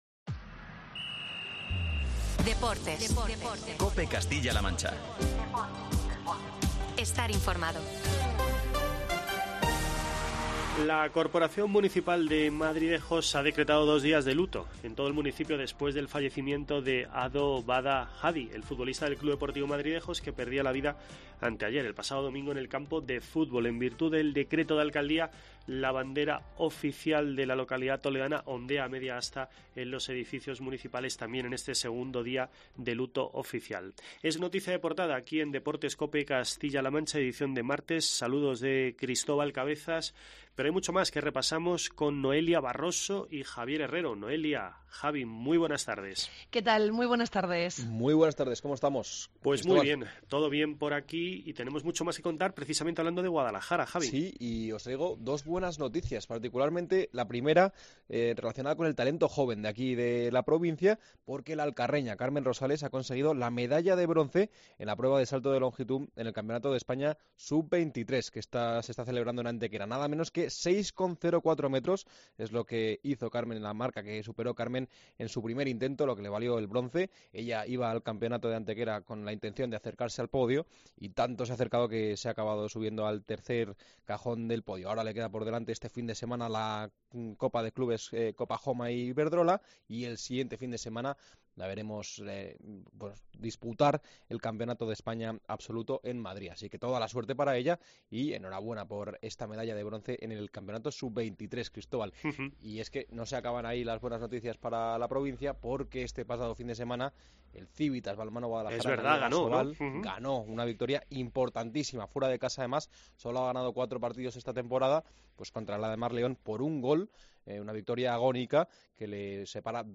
Lo ha anunciado la consejera de Educación, Cultura y Deportes, Rosa Ana Rodríguez, durante la presentación de la primera edición de la I Copa regional Femenina de Ciclismo